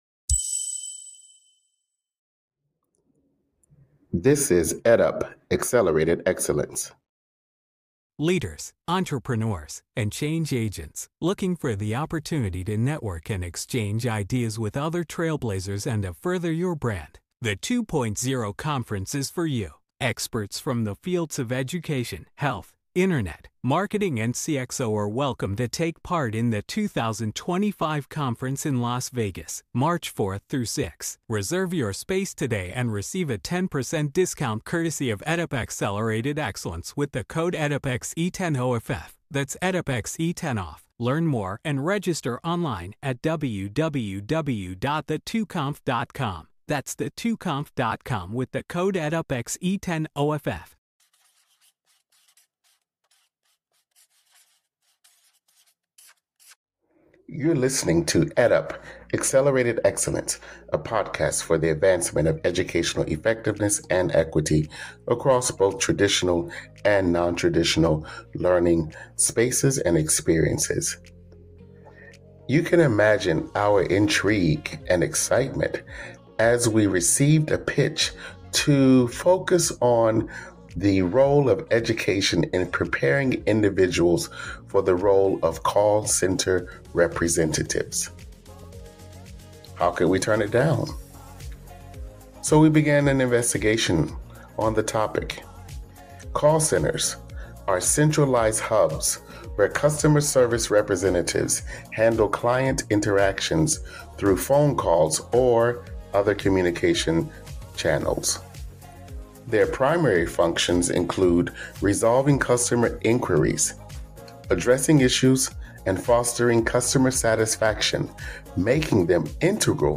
A podcast for the advancement of educational effectiveness and equity for traditional and non-traditional educational experiences and contexts, higher education and beyond. The podcast seeks to engage educators, scholars, entrepreneurs, advocates, and policy makers at all levels.